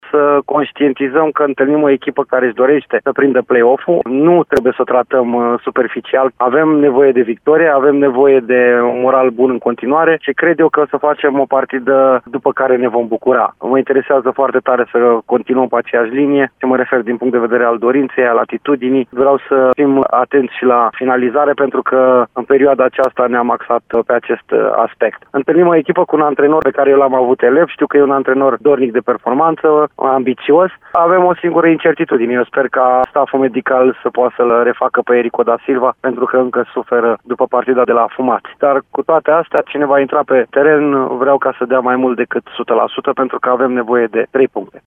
Antrenorul Reșiței își montează jucătorii pentru un meci de la care așteptă trei puncte, pentru a rămâne cu moralul ridicat în lupta de play-off; tehnicianul a vorbit, de asemenea, despre omologul său, pe care l-a avut și elev:
7-nov-21.03-Flavius-Stoican-despre-meciul-cu-Chiajna.mp3